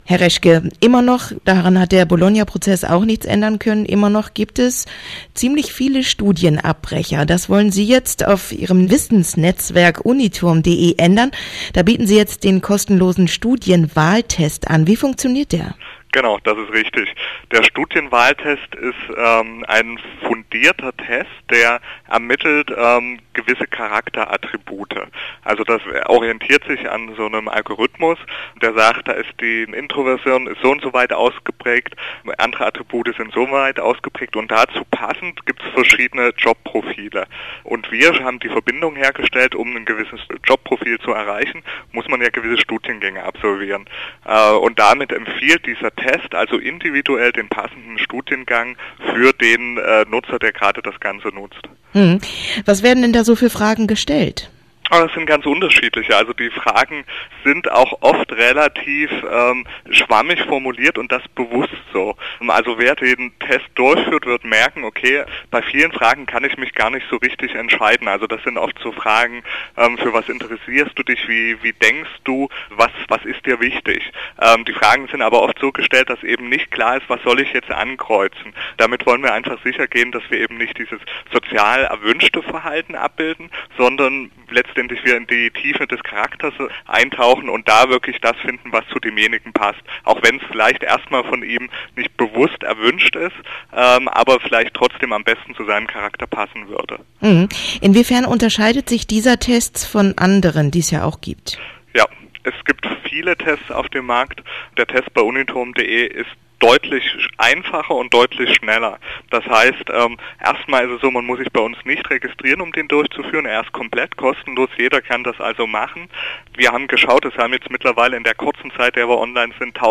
Radio-Interview zum Studienwahltest auf Radio Okerwelle